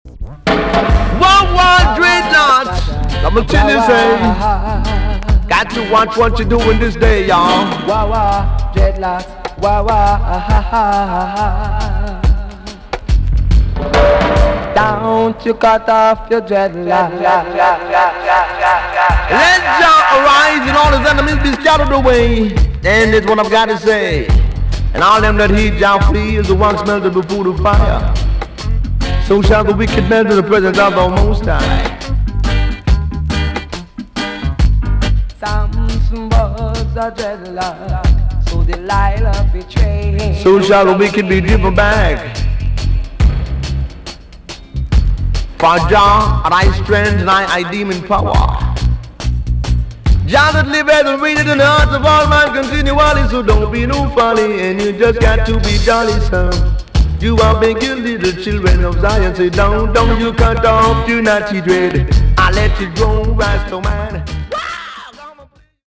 TOP >REGGAE & ROOTS
EX- 音はキレイです。
KILLER ROOTS DJ TUNE!!